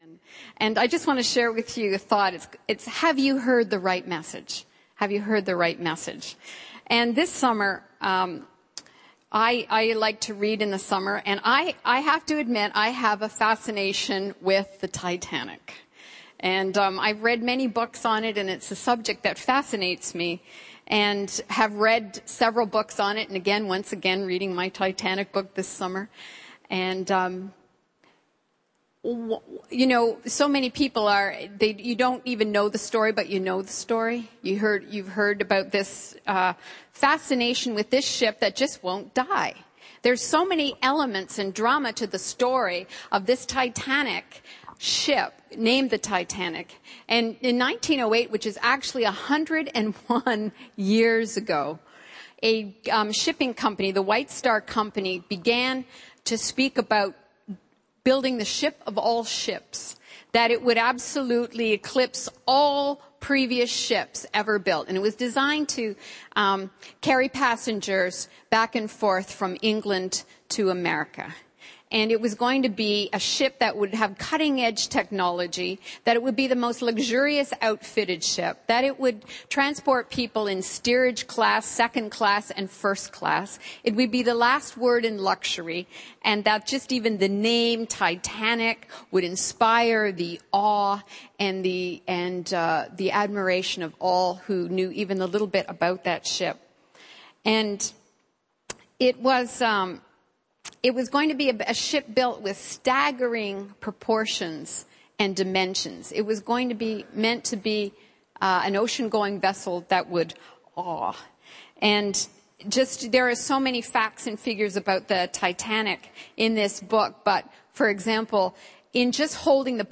Our messages are recorded at Times Square Church in New York City.